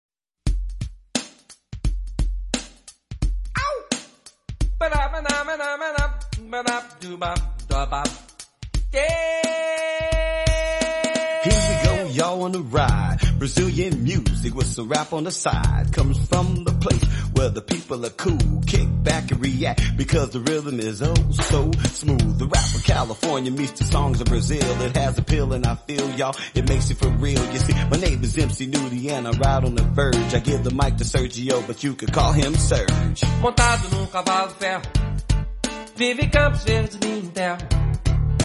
With a velvet voice gives us a romantic journey.